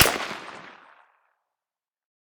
heav_crack_06.ogg